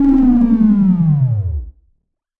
描述：与"Attack Zound02"类似，但衰减时间更长。这个声音是用Cubase SX中的Waldorf Attack VSTi制作的。
Tag: 电子 SoundEffect中